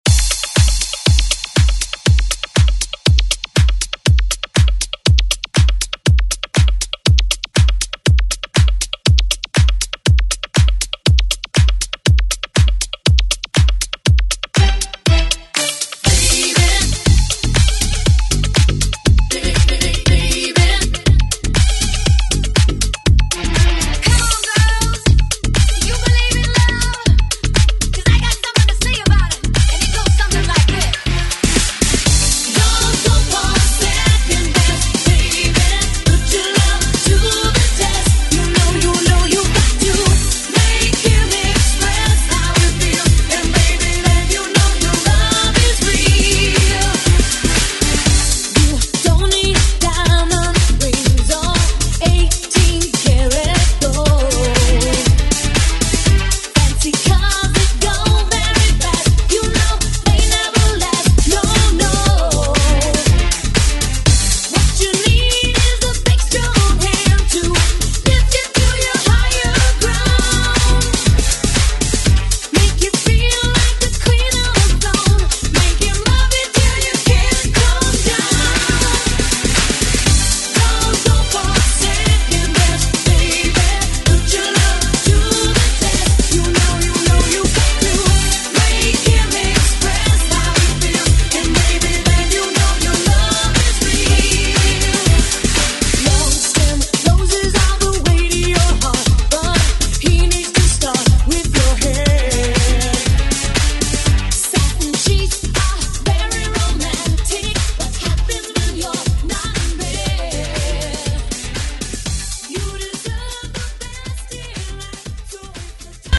Rap Version Throwback Electronic Pop Euro House Music
Extended ReDrum Clean 129 bpm